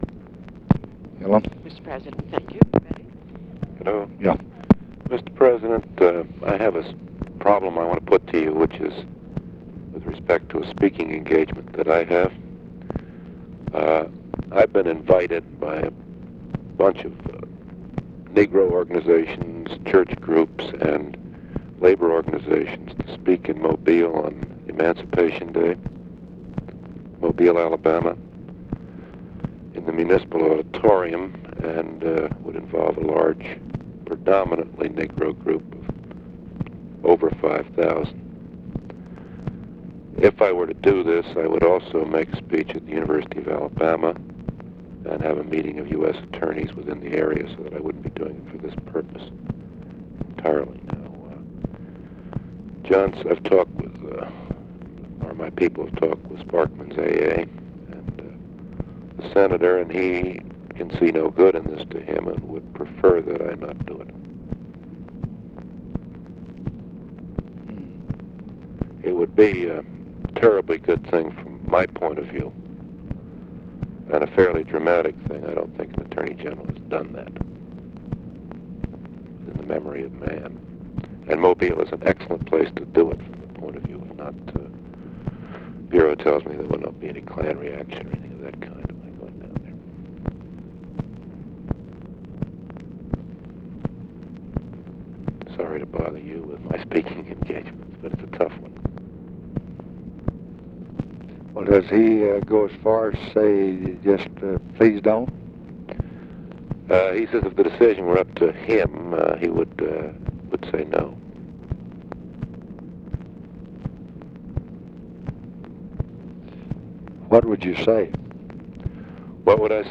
Conversation with NICHOLAS KATZENBACH, December 18, 1965
Secret White House Tapes